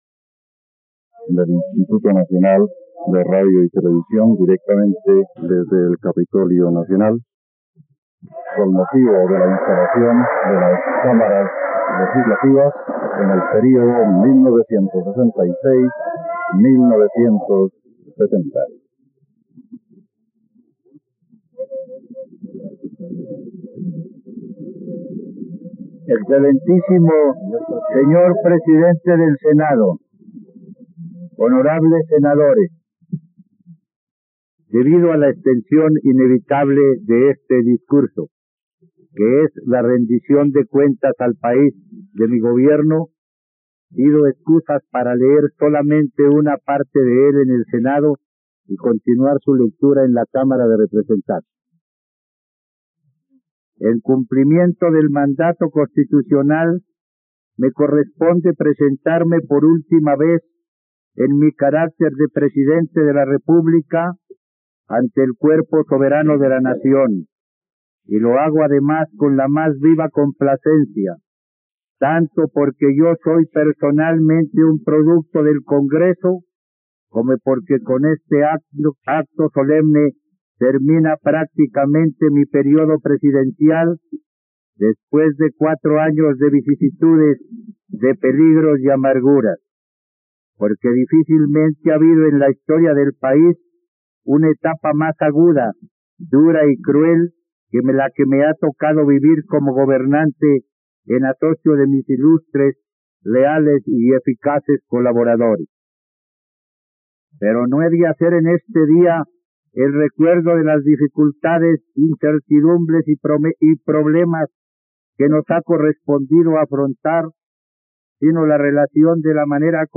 ..Escucha ahora el discurso de Guillermo León Valencia ante el Congreso de la República, el 20 de julio de 1966, en la plataforma de streaming RTVCPlay.